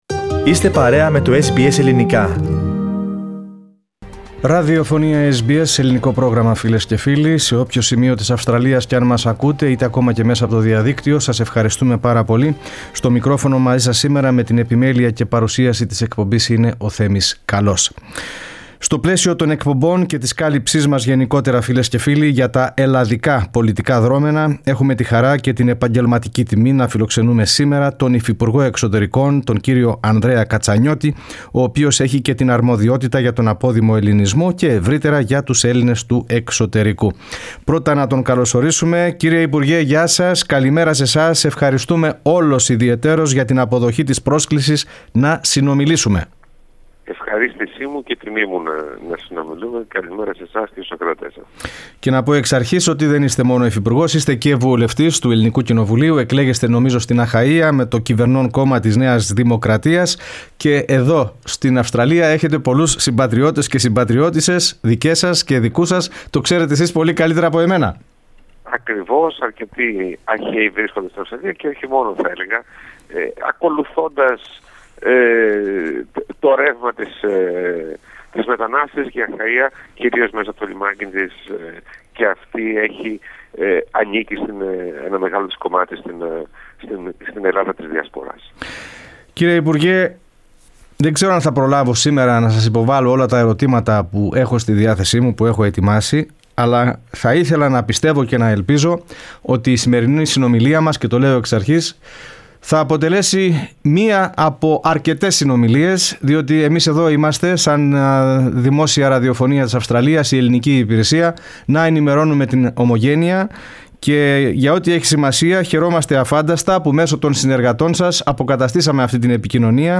Οι σχέσεις Ελλάδας Αυστραλίας, ο επαναπατρισμός της γενιάς brain drain και ένας προσωπικός στόχος που έχει θέσει για τους Έλληνες της διασποράς ήταν ανάμεσα στα θέματα συνέντευξης με τον υφυπουργό Εξωτερικών της Ελλάδας, Ανδρέα Κατσανιώτη.